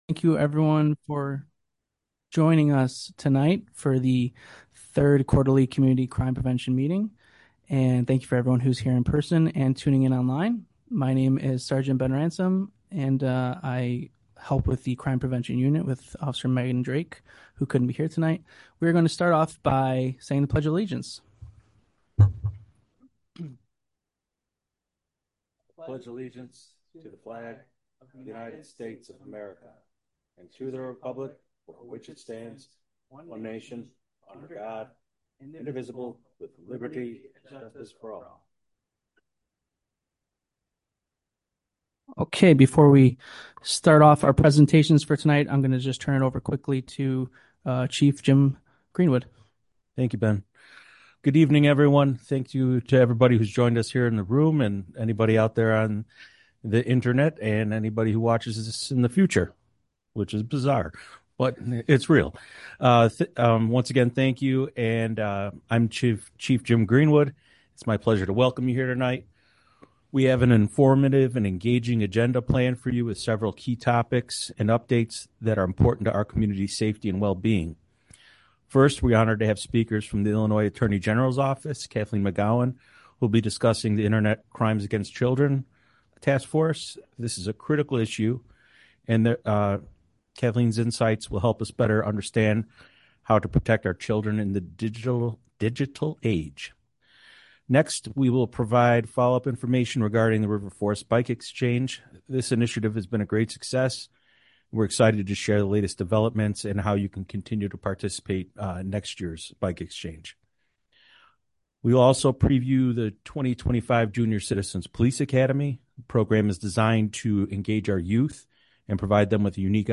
Joint Review Board Meeting - Madison Street TIF District